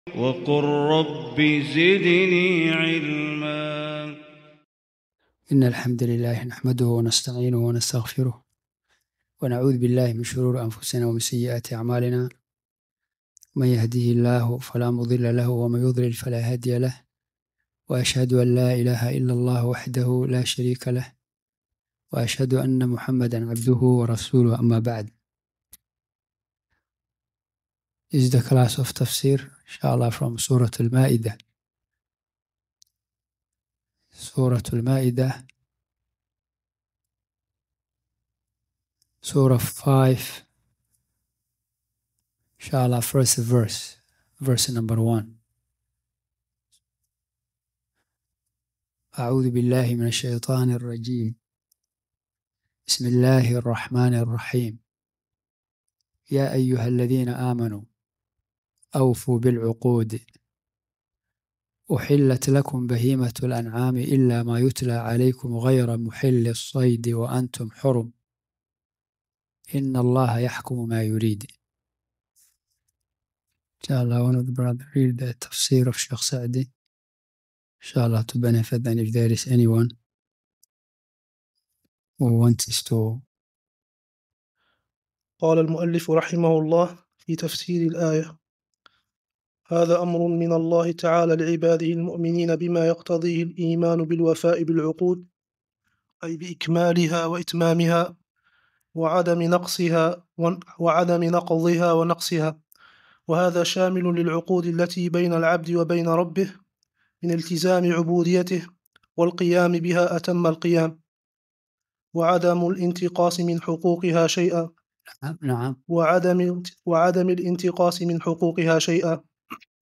Surah Maidah 00:00 Sorry, no results.Please try another keyword Tafsir Quran